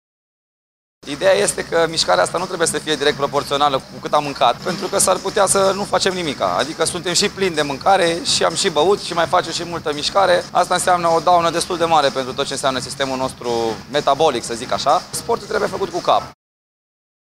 Instructor de fitness